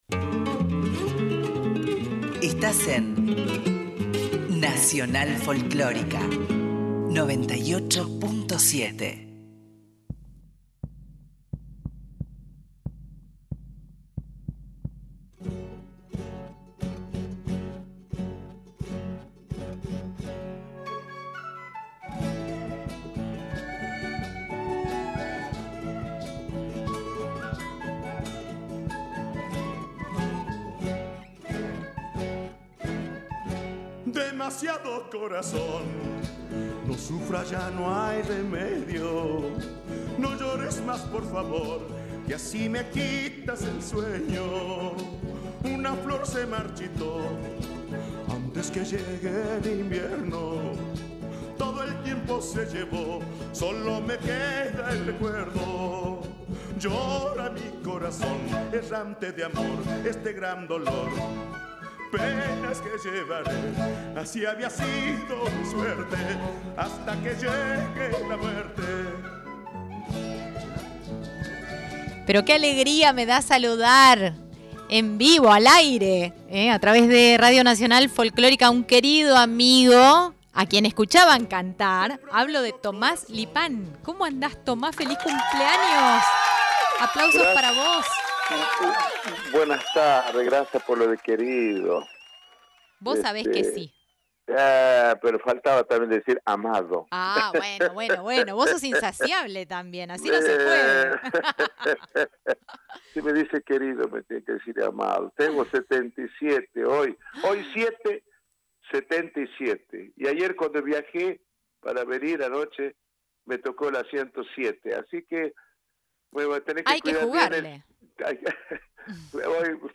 conversó con Tomás Lipán con motivo de su cumpleaños